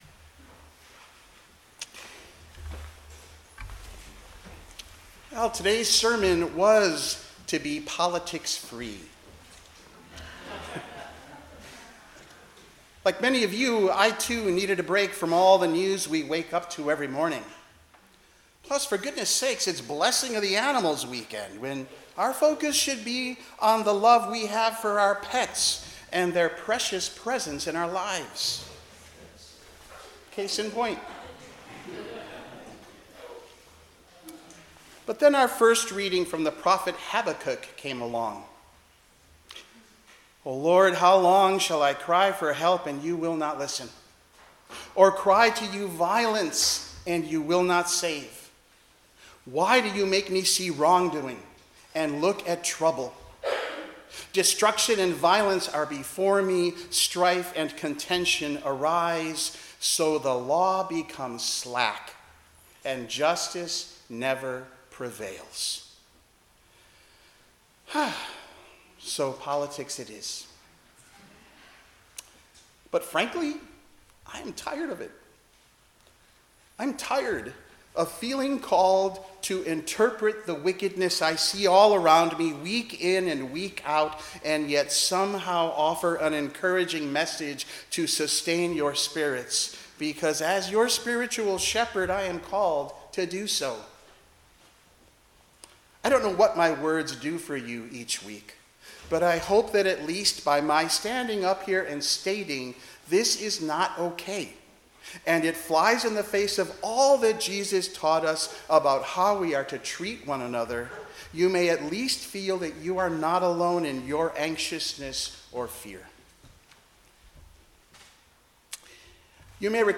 10:00 am Service
Sermon-Seventeenth-Sunday-after-Pentecost-October-5-2025.mp3